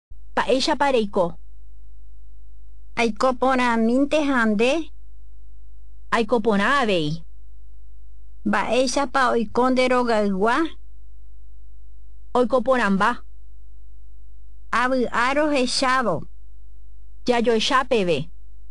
DIALOGS FOR MEETING AND GREETING
Press speaker buttons to hear native speakers realizing the dialogue.